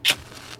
SFX / Auras / Burning / Ignition